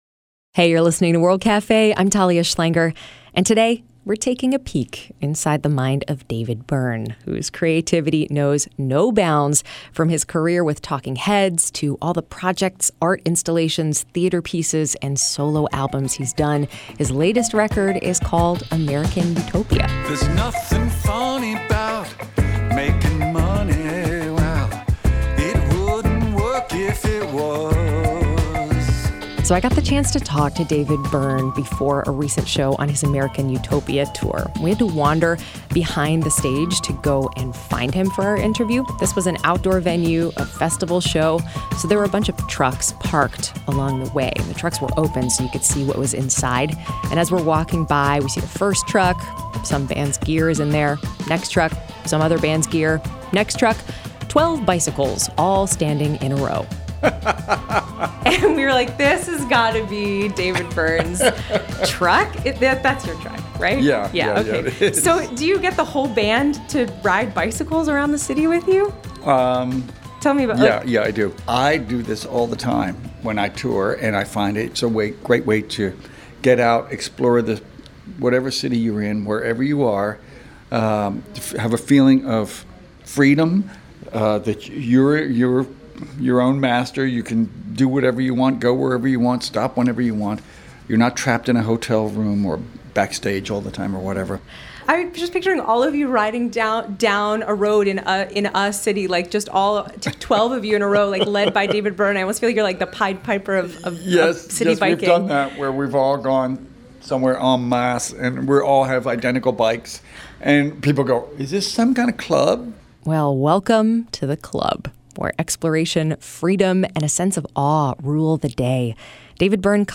David Byrne, musician and all-around wonder archaeologist, visits the World Cafe Studios in Philadelphia to talk about animals, bicycles, civic obligations and his latest album 'American Utopia.'